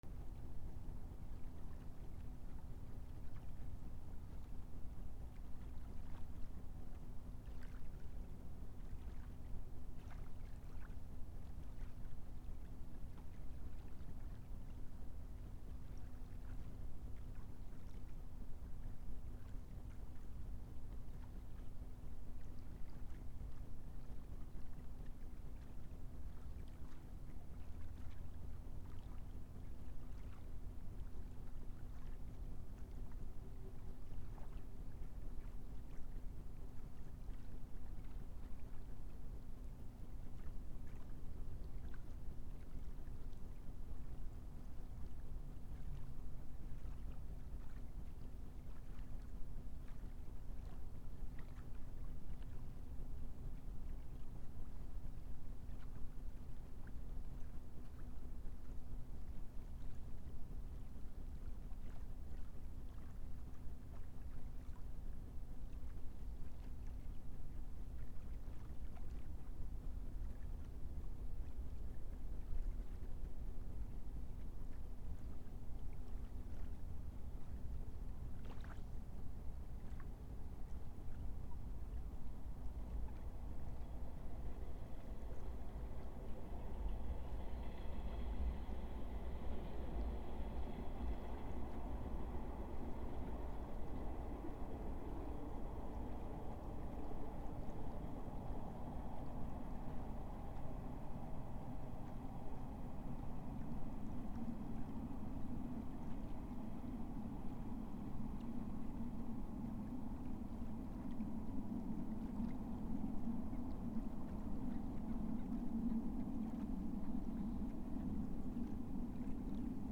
/ B｜環境音(自然) / B-10 ｜波の音 / 波の音
琵琶湖8 波打ち際まで約20m
チャ NT4